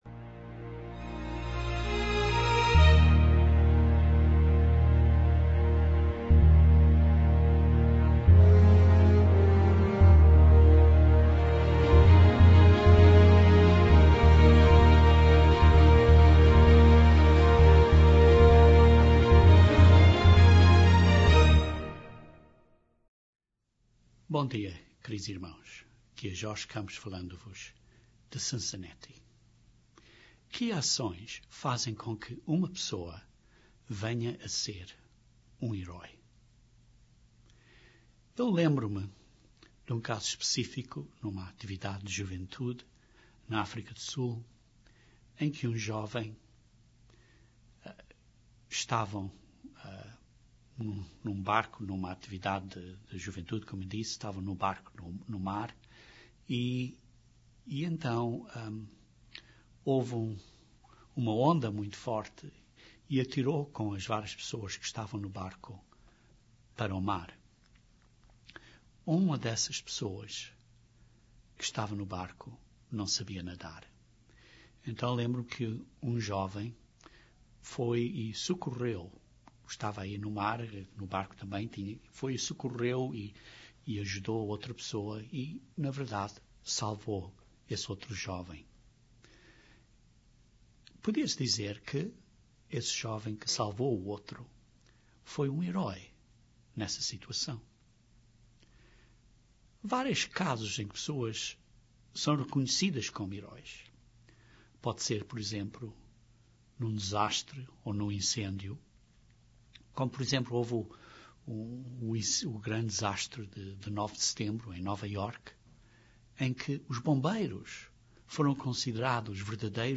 Este sermão descreve algumas características do nosso Grande Herói, características estas que precisamos imitar.